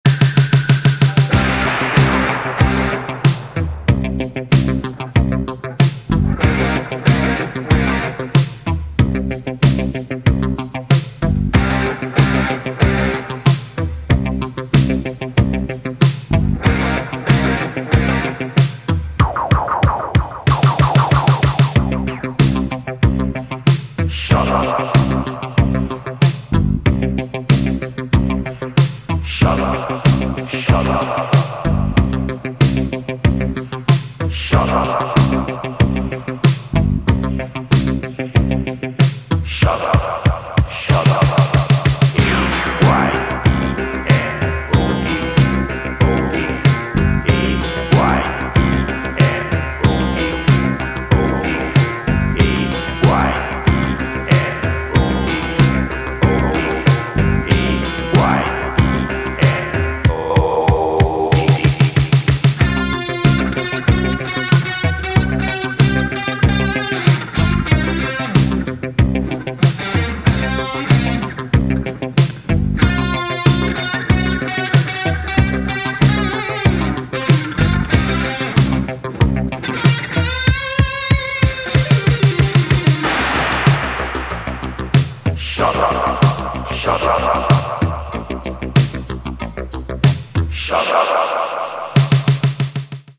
two new electronic funk/rock tracks